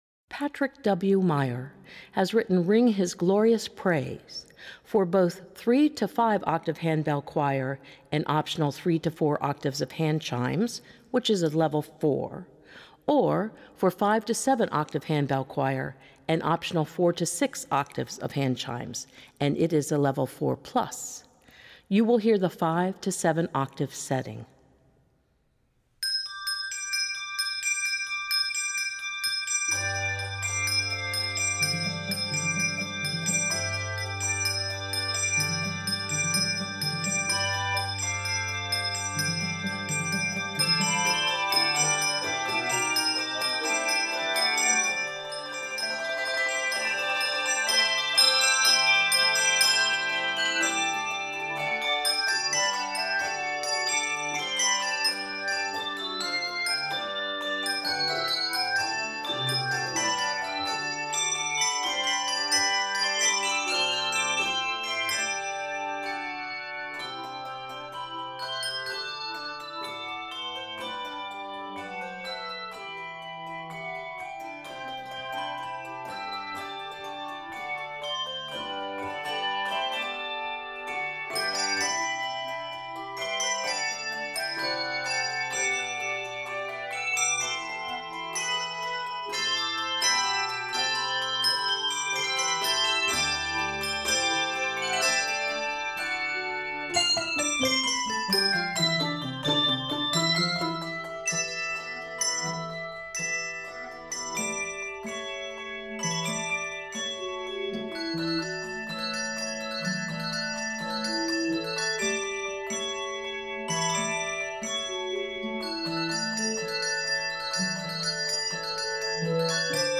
Rhythmic, fresh, and joyous
N/A Octaves: 3-7 Level